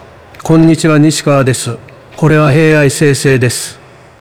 myvoice.wav(0.38MB)、AI生成音声
myvoiceAI.wav